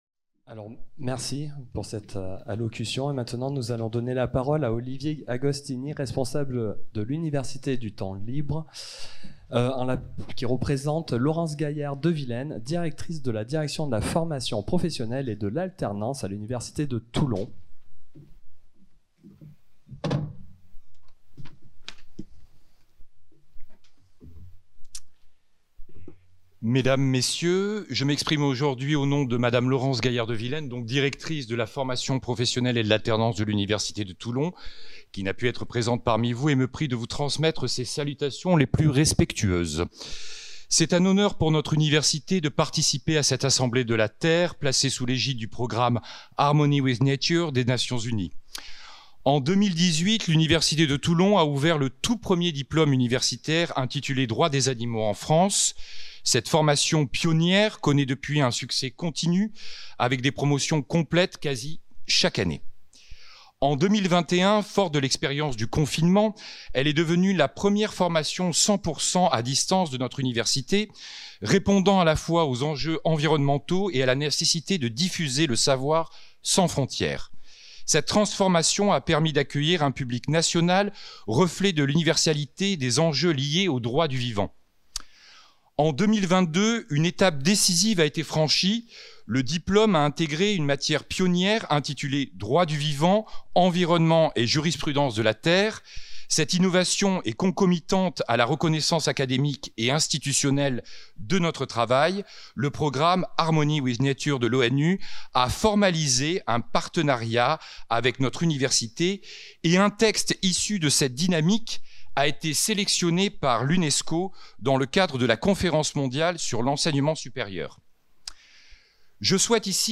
Discours